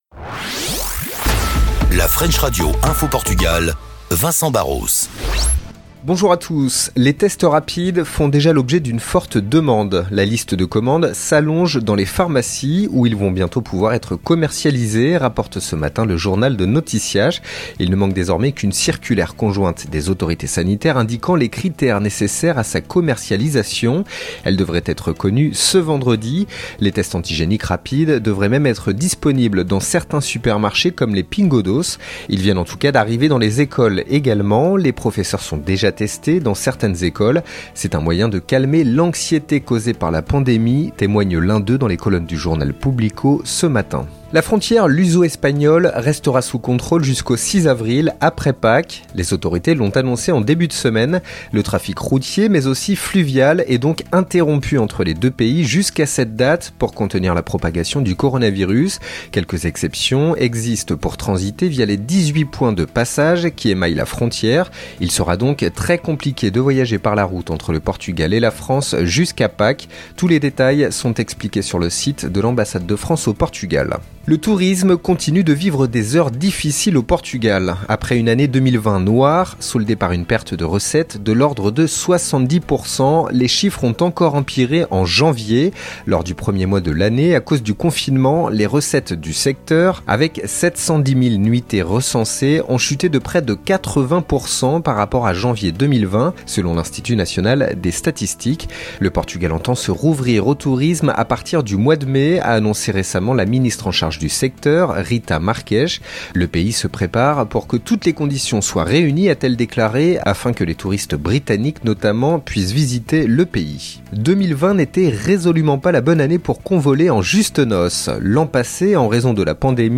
3. Flash Info - Portugal